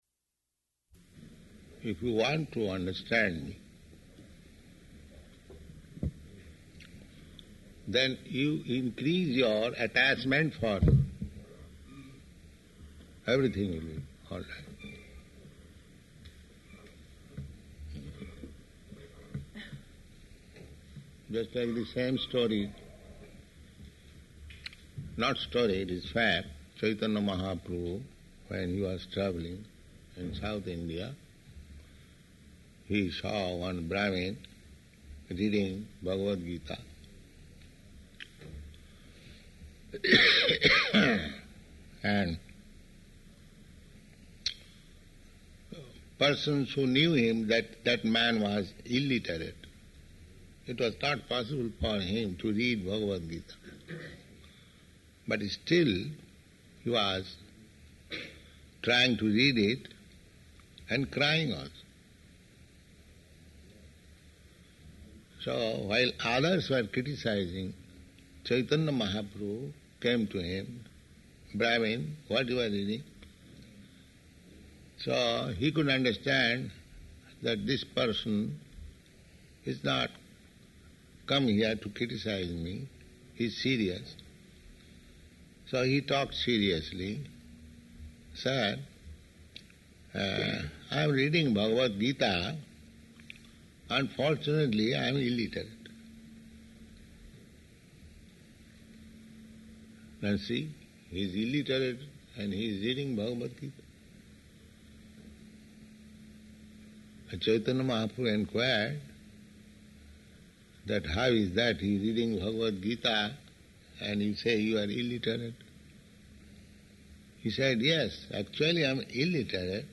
Lecture [partially recorded]
Type: Lectures and Addresses
Location: Los Angeles